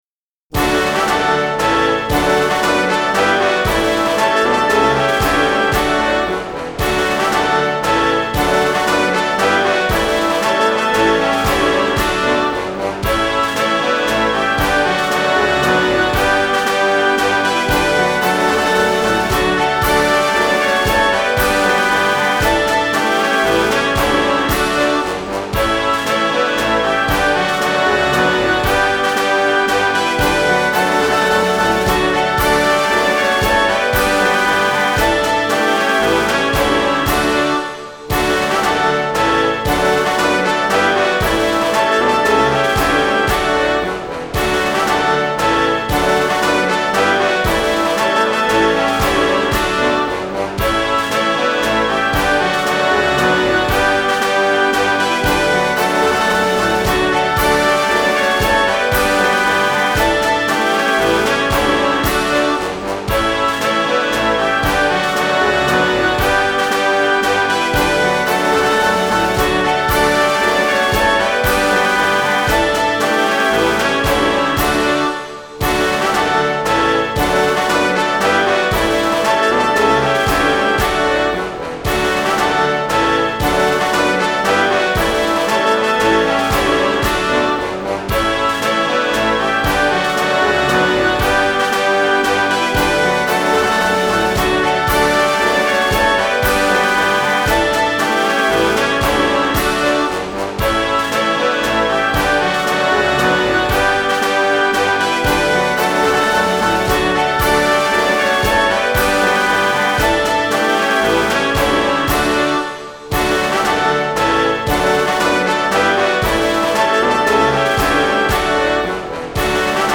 hymn-polski-d-dur.mp3